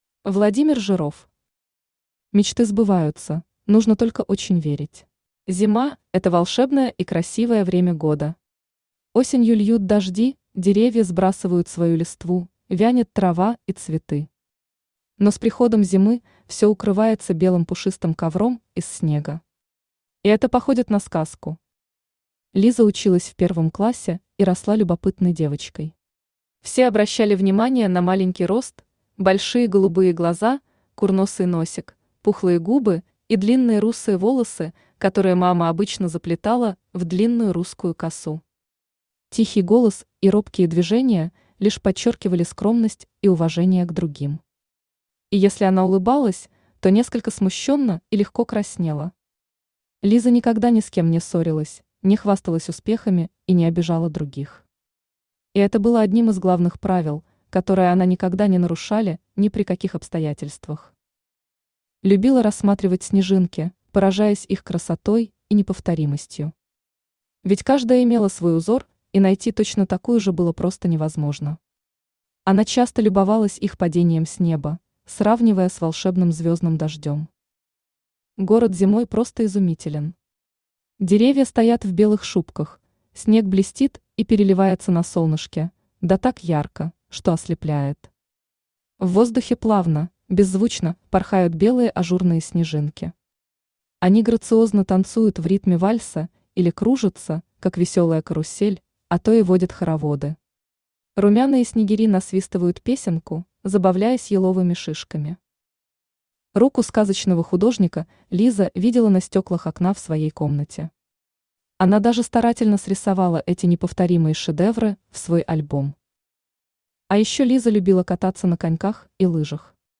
Аудиокнига Мечты сбываются, нужно только очень верить | Библиотека аудиокниг
Aудиокнига Мечты сбываются, нужно только очень верить Автор Владимир Николаевич Жиров Читает аудиокнигу Авточтец ЛитРес.